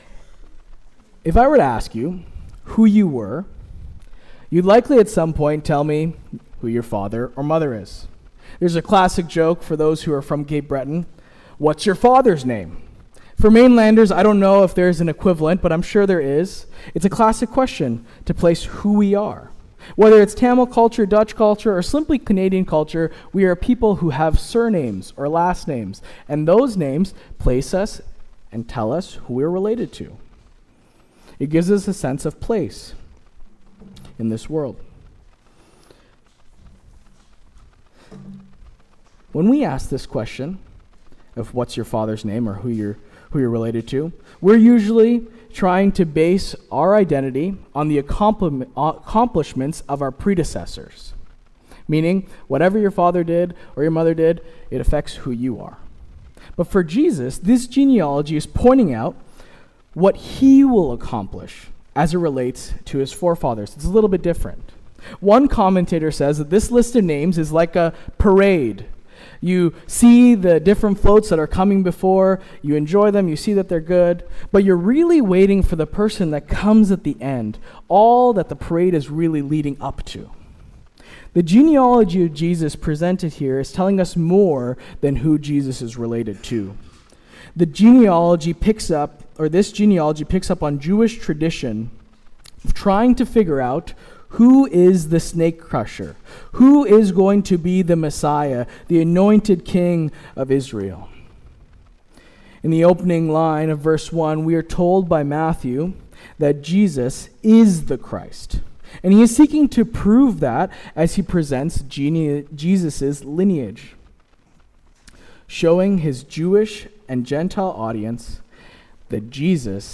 Sermon “The Promised Messiah”